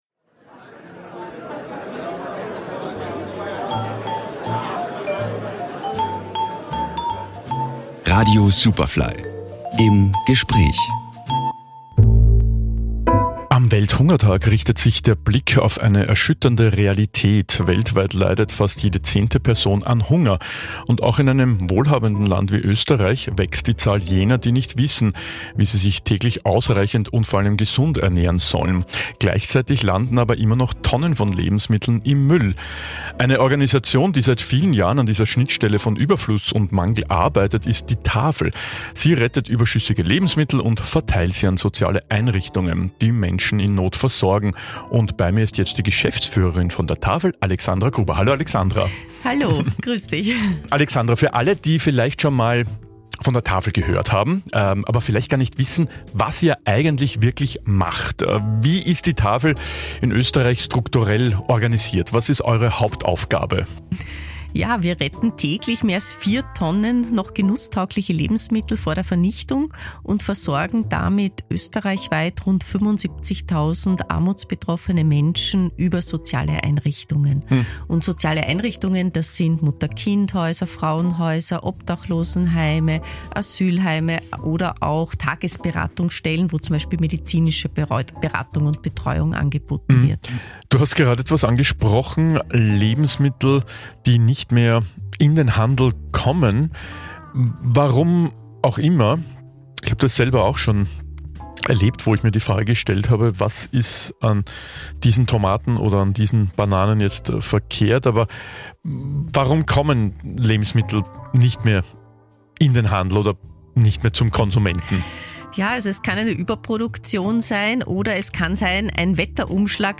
Superfly im Gespräch | Die Tafel Österreich